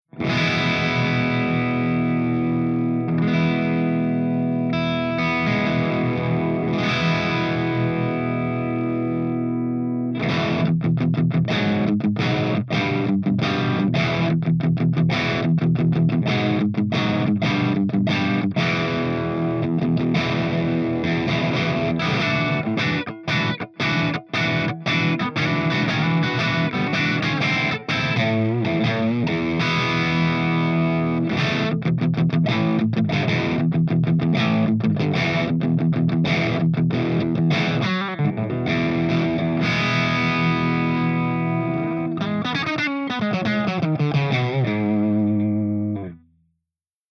092_PEAVEY_CRUNCHDRIVE_GB_P90.mp3